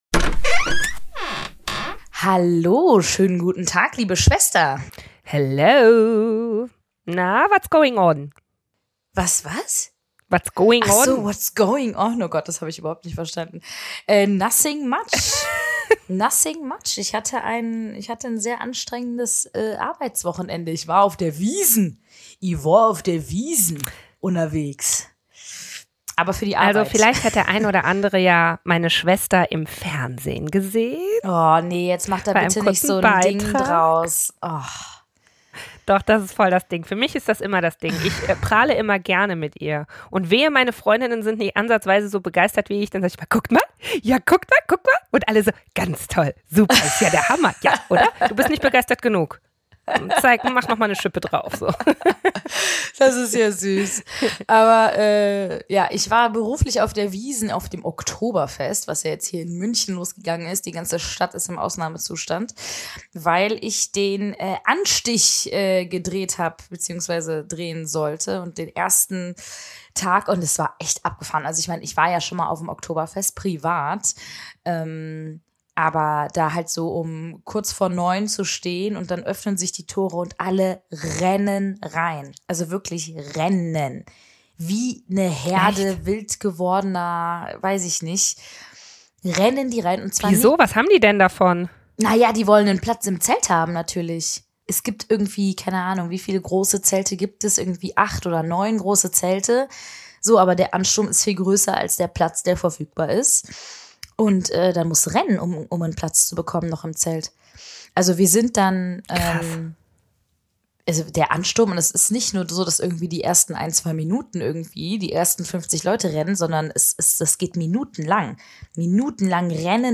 In dieser Folge sprechen die Schwestern über ihre eigenen Erfahrungen mit dem Thema Mobbing: Wie wird man von Opfer zu Täter? Was kann jedes Elternteil tun, um seine Kinder auch präventiv zu schützen?
Wir sprechen offen und locker über diese Themen - gegen Stigmatisierung und für Offenheit und Toleranz.